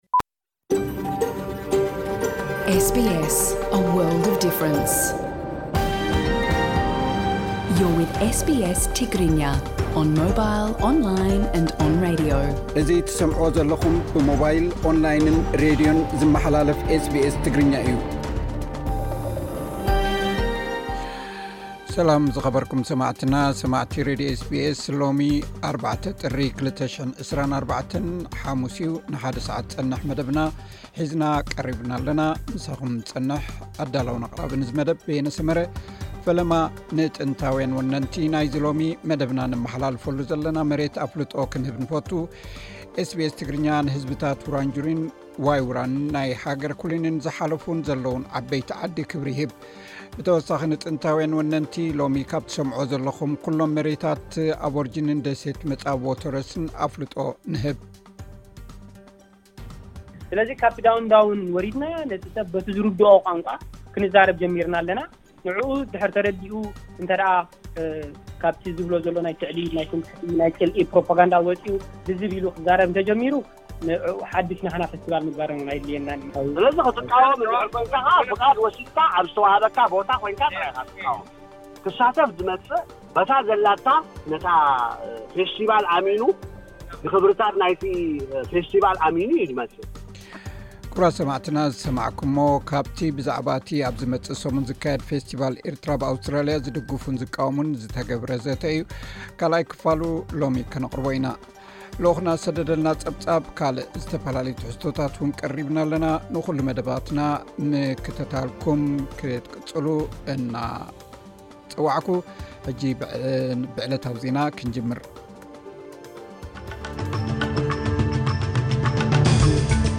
SBS Studio 5 Melbourne